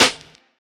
CardiakSnare.wav